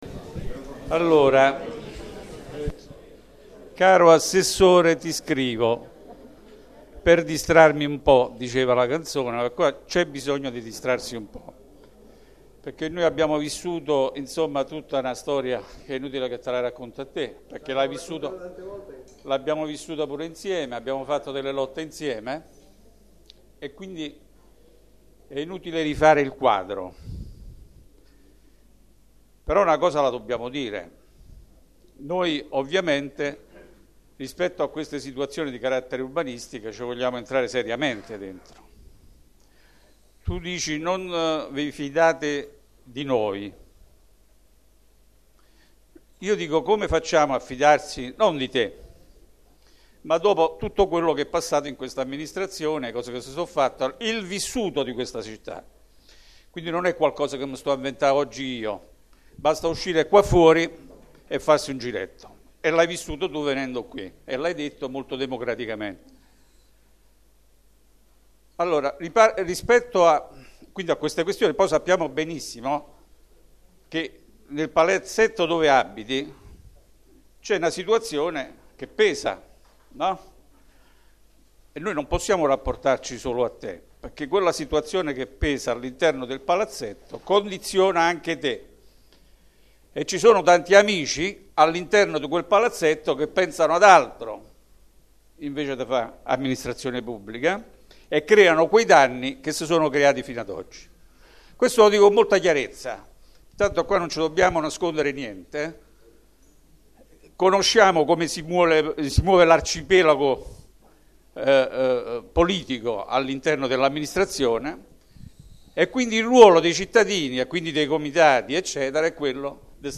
Registrazione integrale dell'incontro svoltosi il 10 giugno 2014 presso la Sala Rossa del Municipio in Piazza di Cinecittà, 11